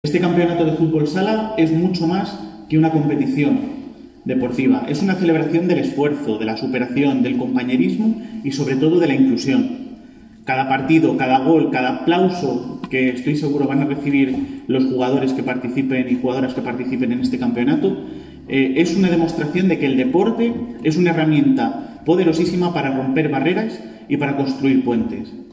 Declaraciones del alcalde, Miguel Óscar Aparicio